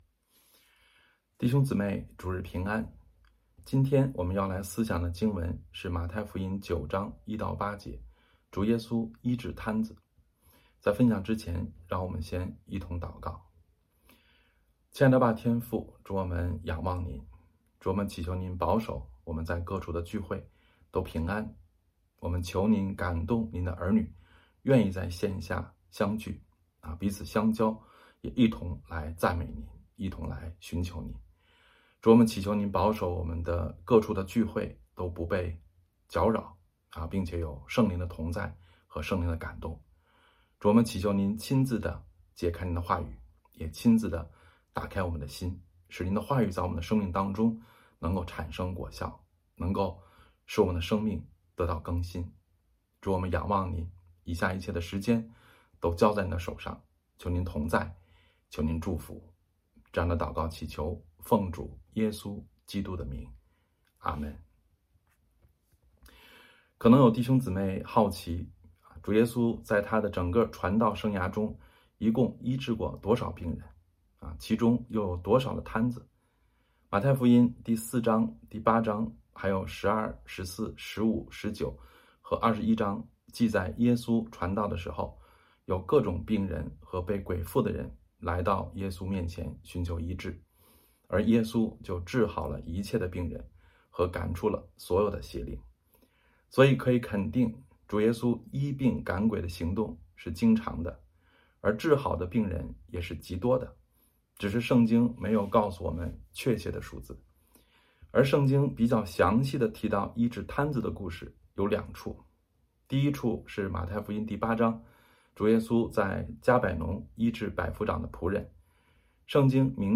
北京守望教会2025年3月30日主日敬拜程序